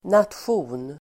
Uttal: [natsj'o:n]